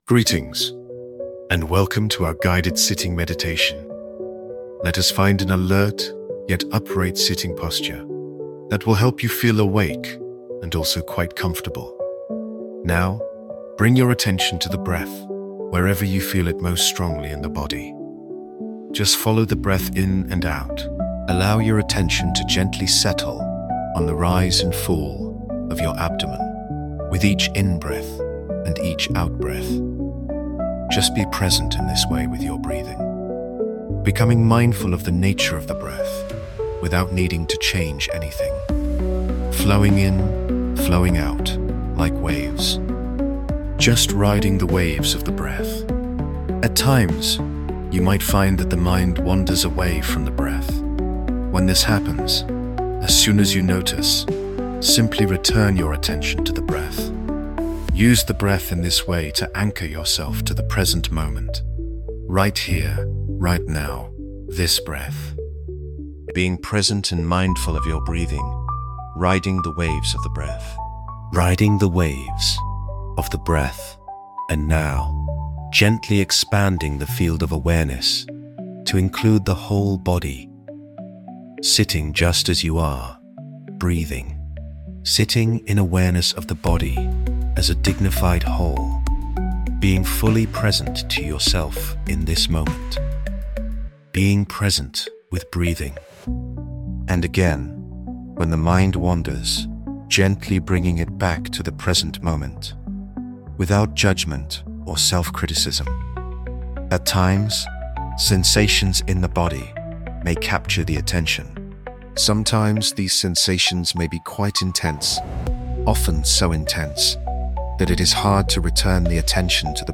sitting_meditation.mp3